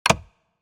Racing, Driving, Game Menu, Ui Confirm Sound Effect Download | Gfx Sounds
Racing-driving-game-menu-ui-confirm.mp3